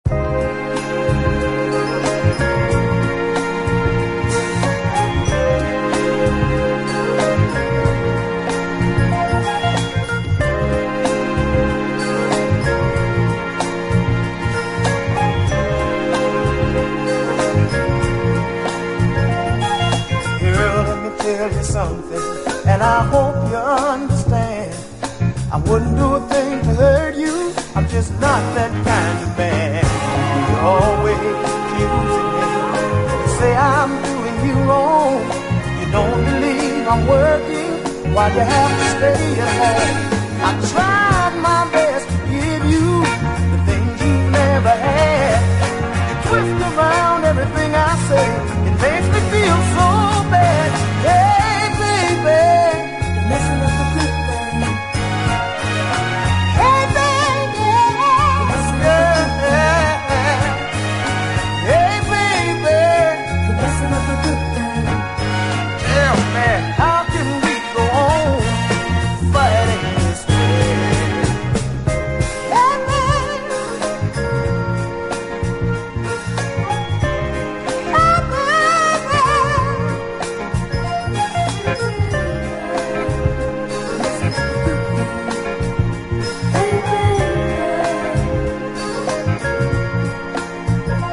溌剌ヤング・ソウル45